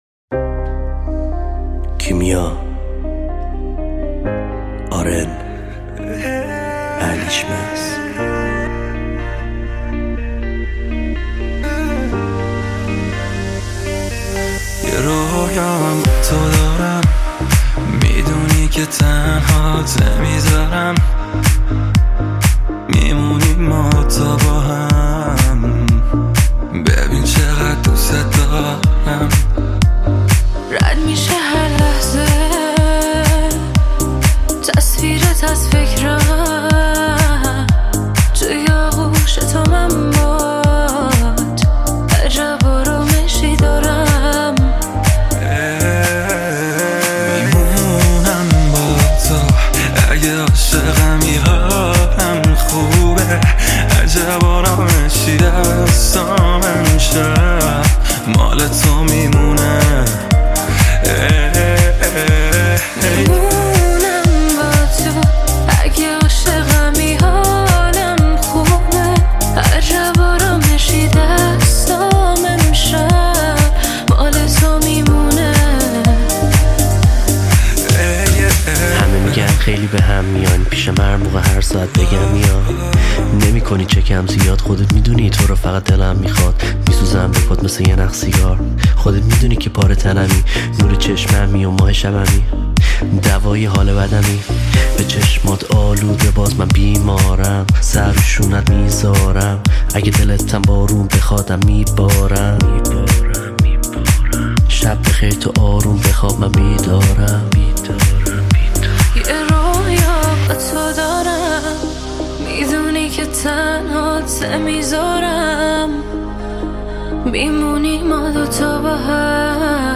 آهنگ جدید و غمگین